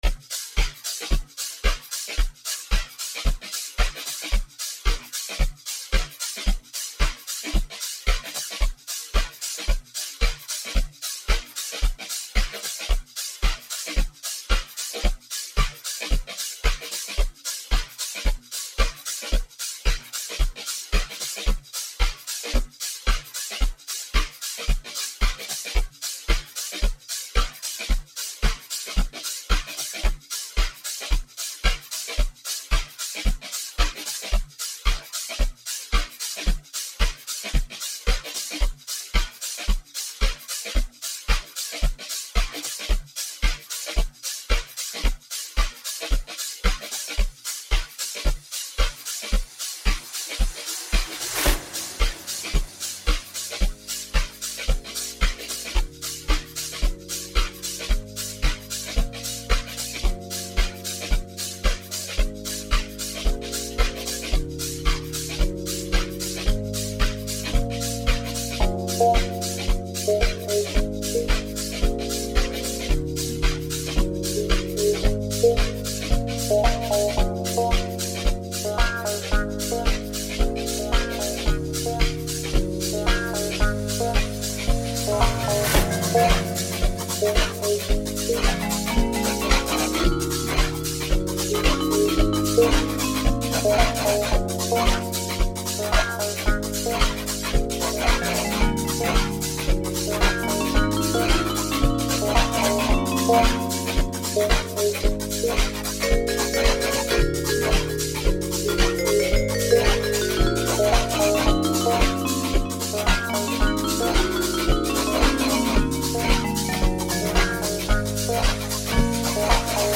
melodic piano offering